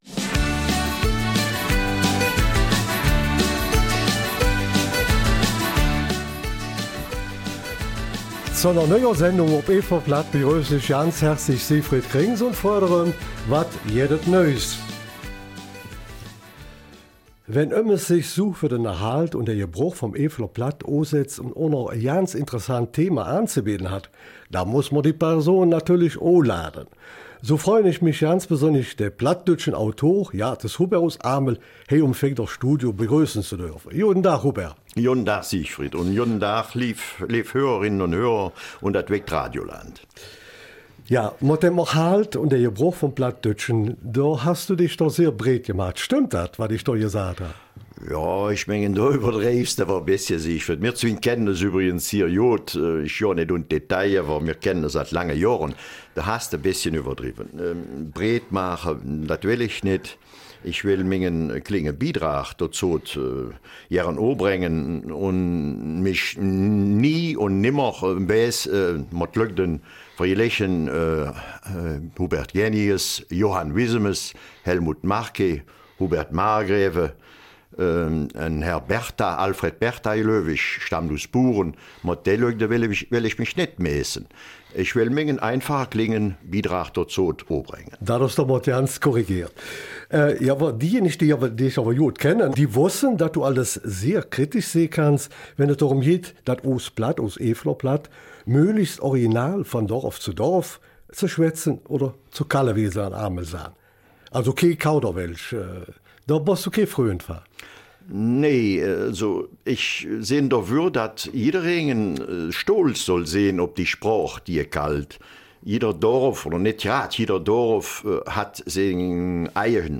Eifeler Mundart: ZVS-Reihe ''Menschen in Beruf, Handel und Handwerk''